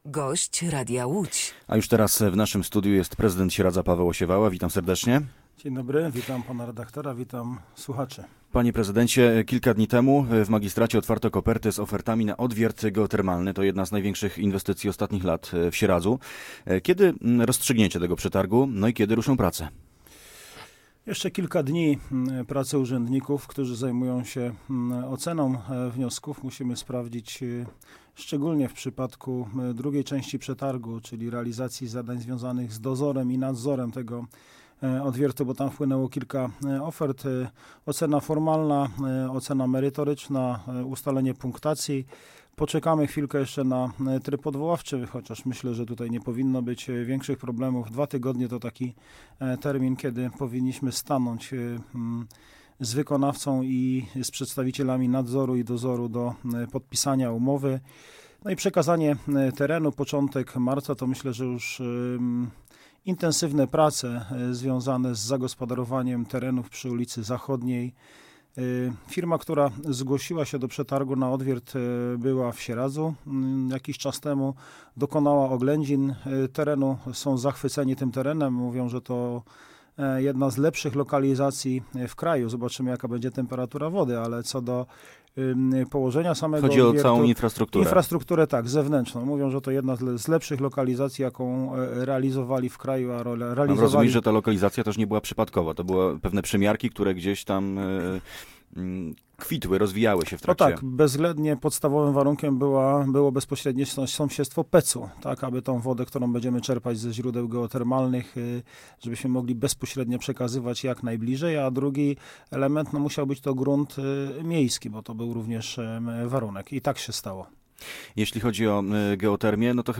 Posłuchaj całej rozmowy: Nazwa Plik Autor – brak tytułu – audio (m4a) audio (oga) Warto przeczytać Kolejny transfer Widzewa!